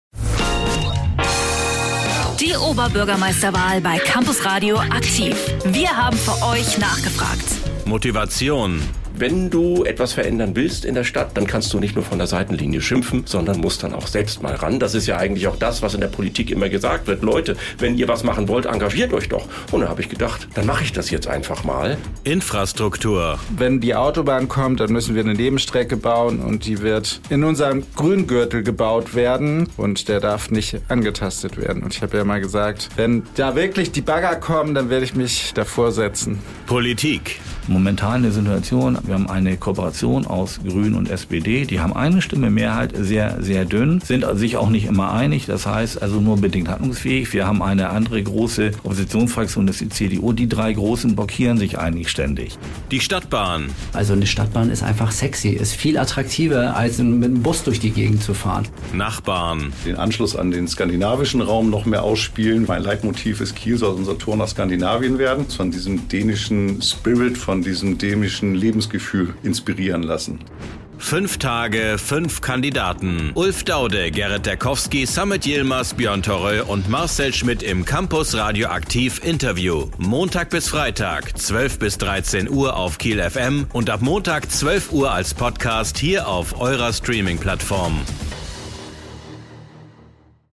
Teaser Oberbürgermeisterwahl in Kiel - die Interviews